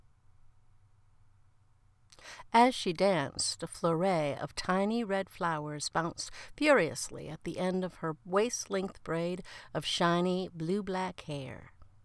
One is straight snowball, no voicemeeter.
(None of that was saved, what you’re hearing is unedited.)
Straight snowball: